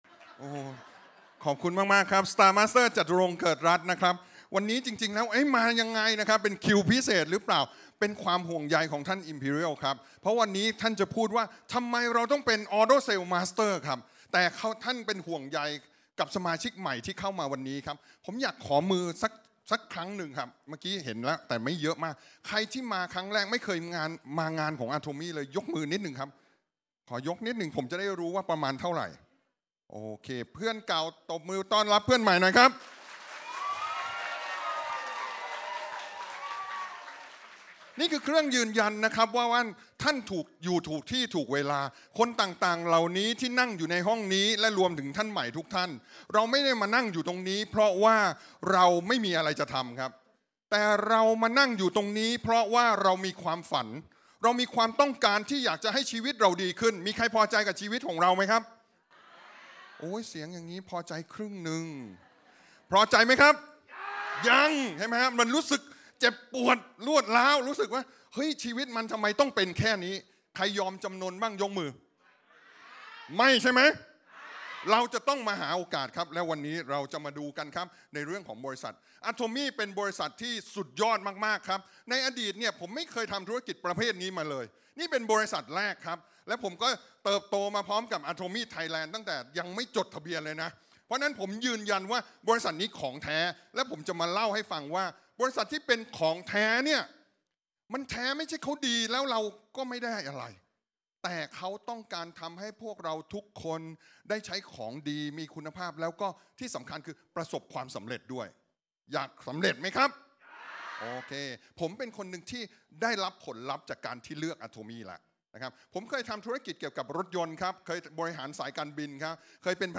Special Lecture 26 สิงหาคม 2024รู้จักอะโทมี่ และแผนรายได้ที่ยุติธรรม